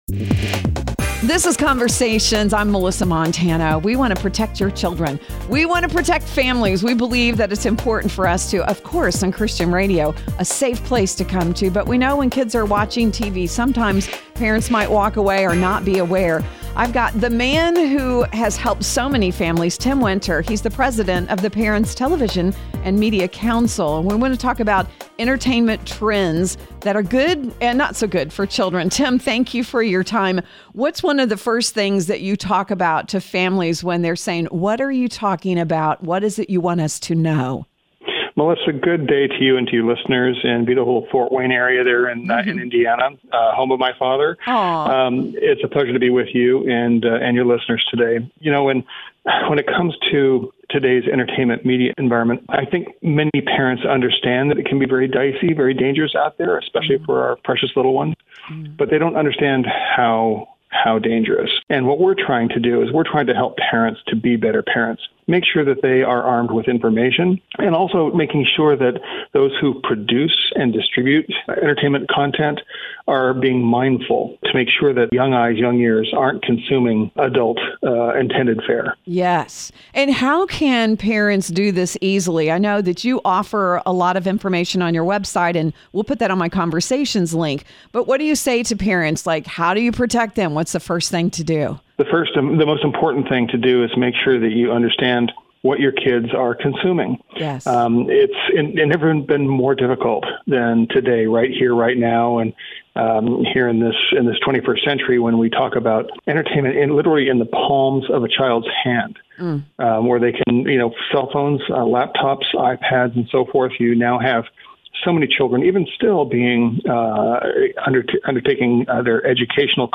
For other interviews covering several different topics heard on Conversations